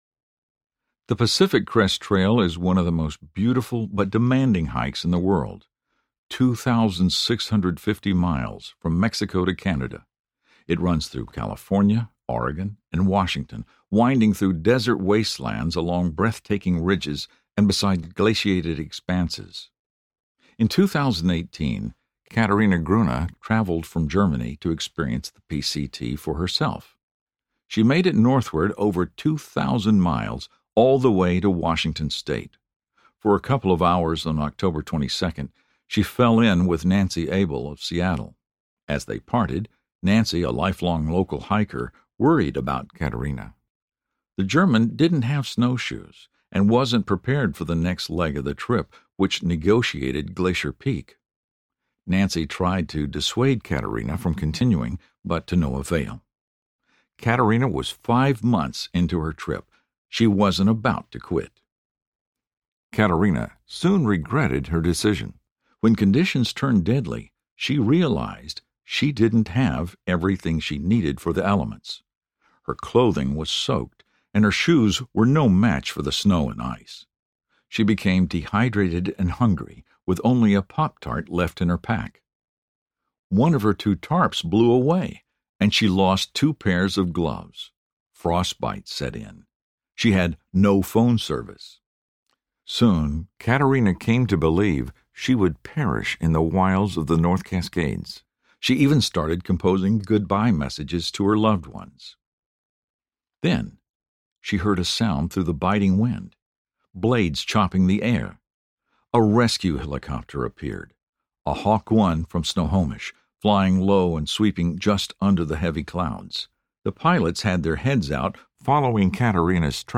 Everything You Need Audiobook
Narrator